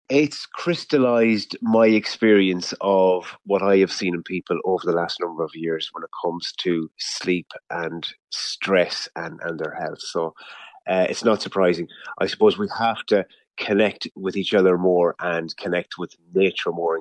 Sleep Expert, says the results are not surprising: